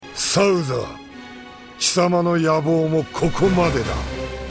Apparently some people complained about Raoh sounding too soft in the cinematic version.